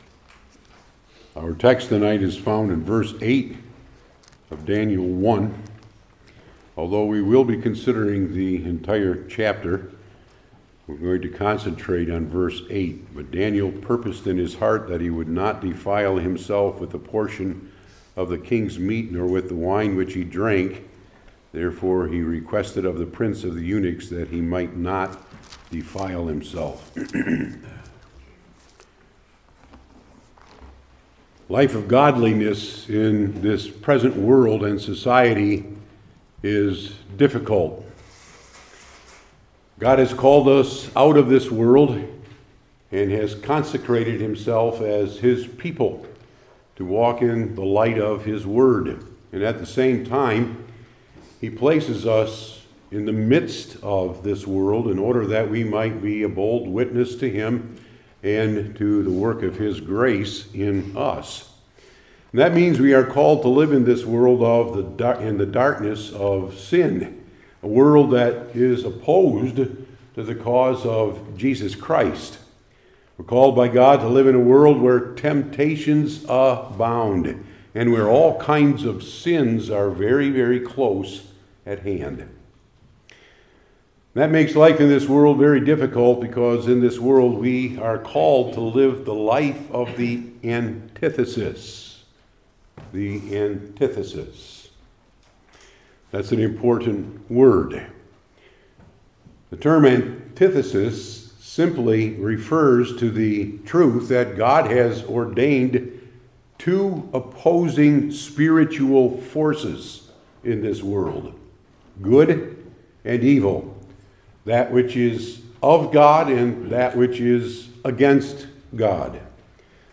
Daniel 1:8 Service Type: Old Testament Individual Sermons I. The Antithesis Revealed II.